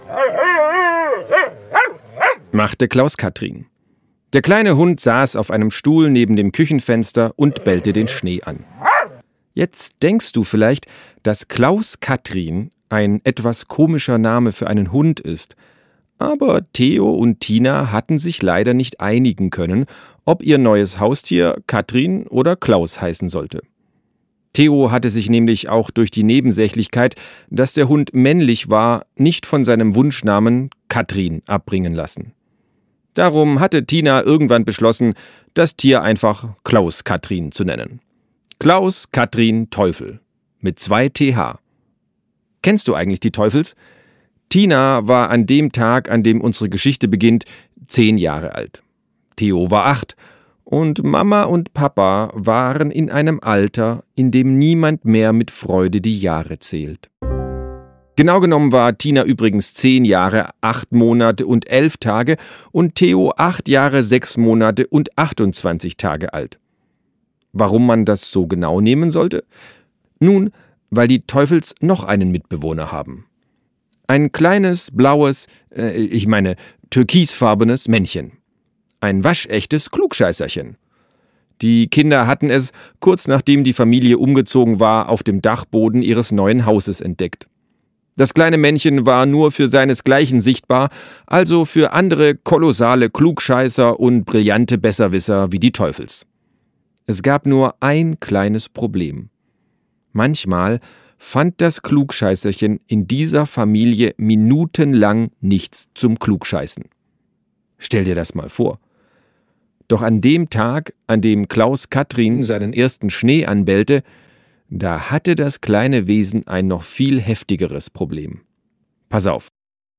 Sprecher Marc-Uwe Kling